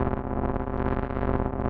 Index of /musicradar/rhythmic-inspiration-samples/140bpm
RI_ArpegiFex_140-01.wav